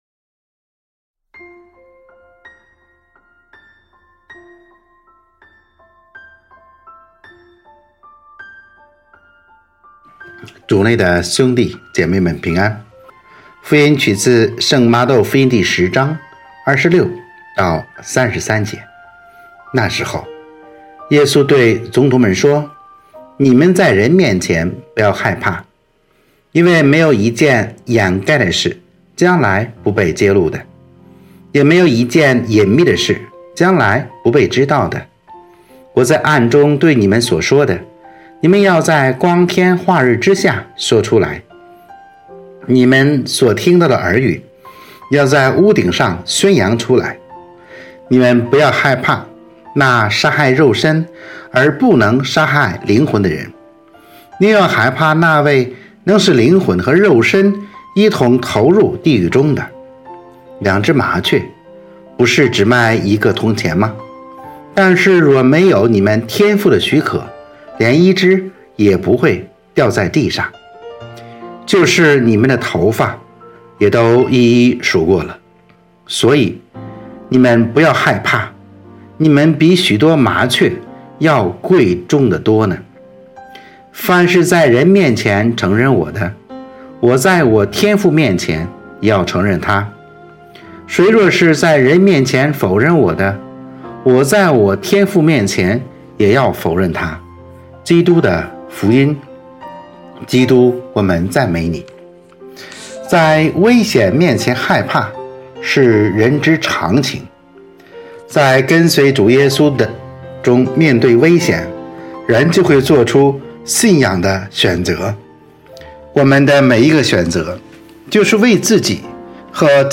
【主日证道】| “害怕”和“不害怕”（甲-常年期第12主日）